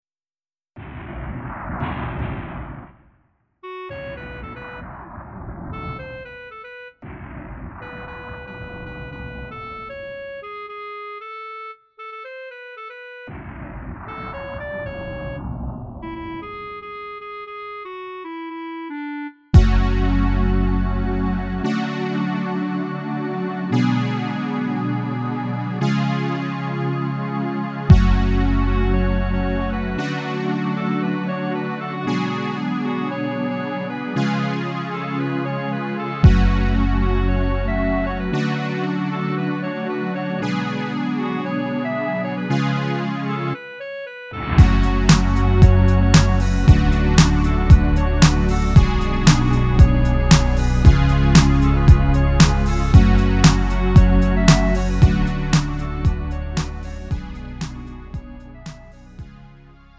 음정 -1키 3:27
장르 가요 구분